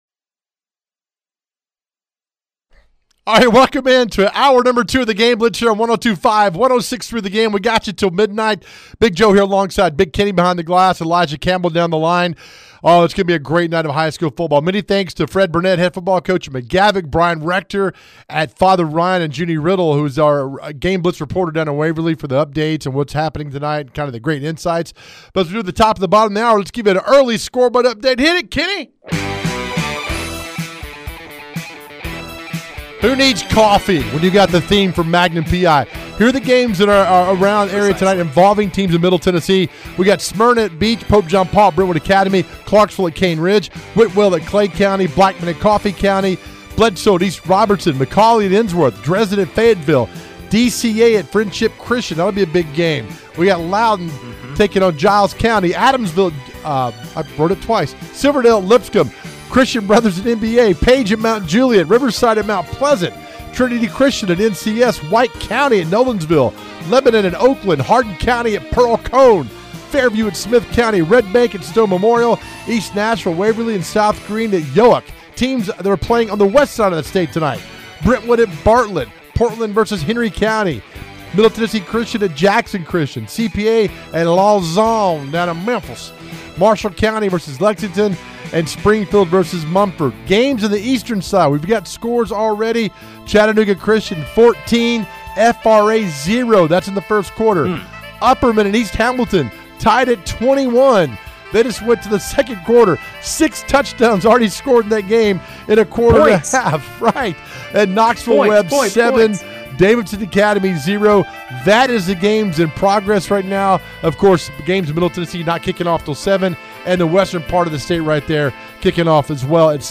We are in round two of TN High School Football and we cover it all with head coach and reporter interviews!